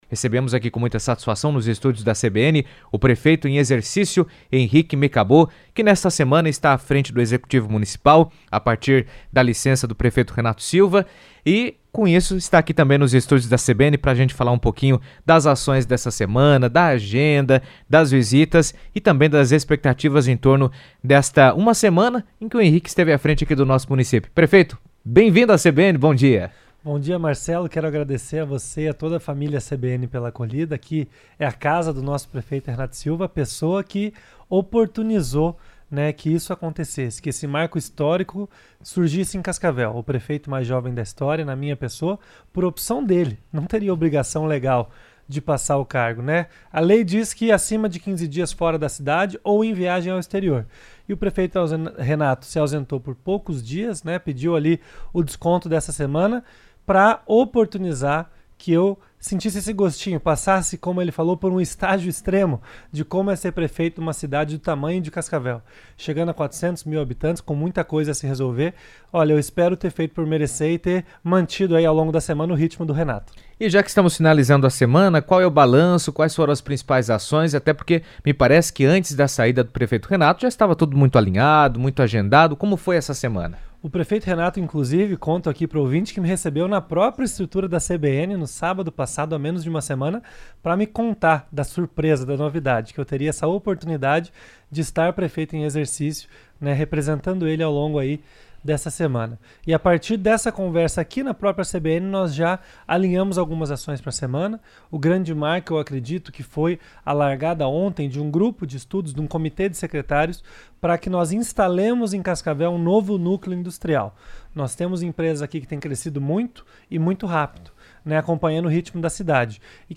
O prefeito interino de Cascavel, Henrique Mecabô, esteve na rádio CBN e apresentou um balanço das ações realizadas nesta semana em que esteve à frente do Executivo Municipal. O destaque, ficou para o início dos estudos para implantação de novo núcleo industrial.